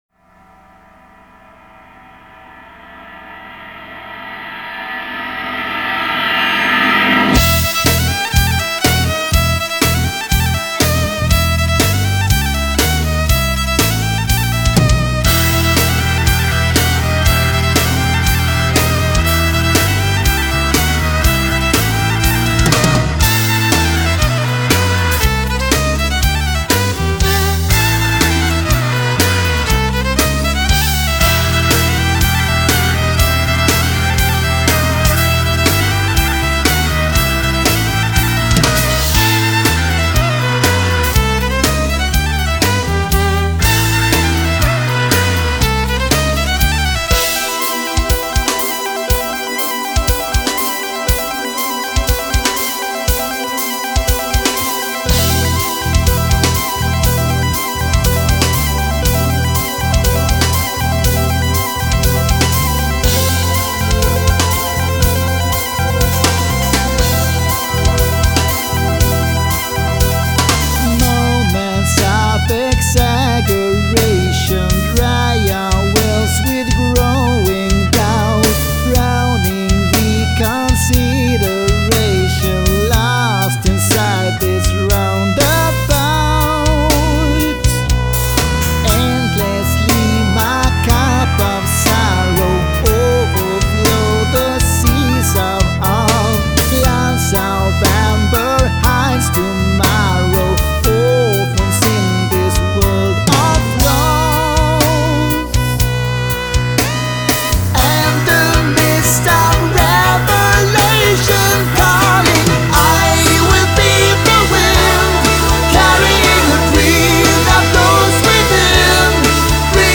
компенсируется скрипкой и клавишами, что, по правде говоря,